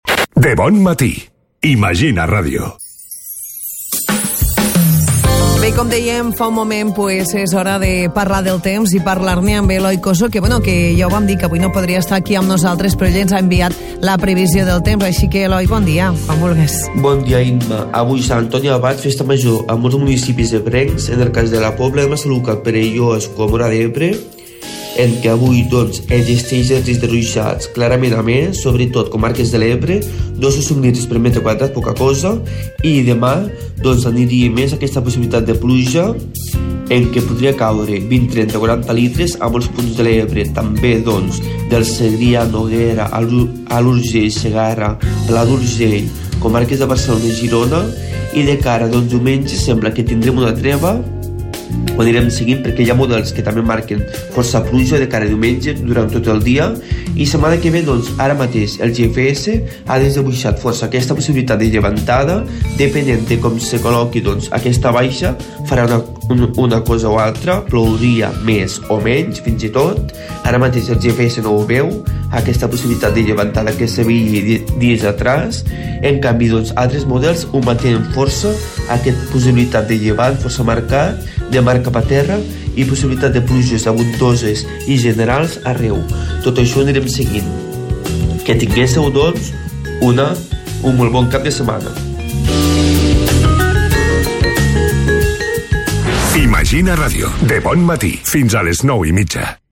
previsió del temps